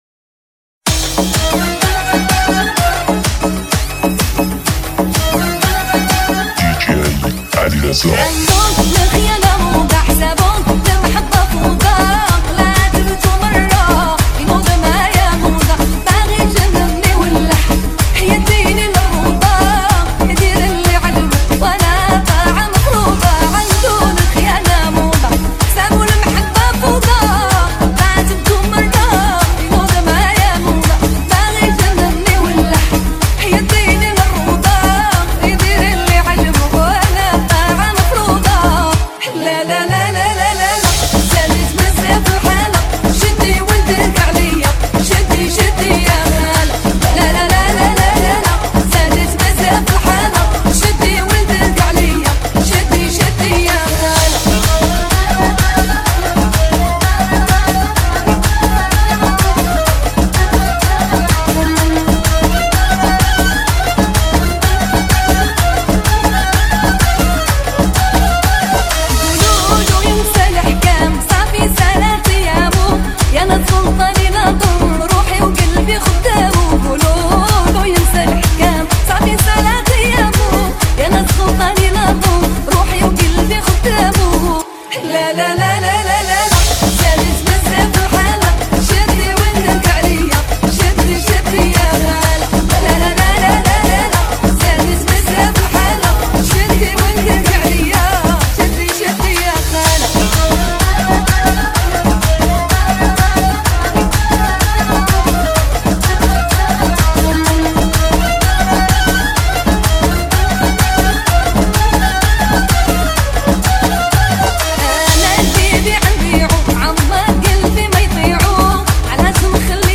آهنگ عربی لالالا خواننده زن ریمیکس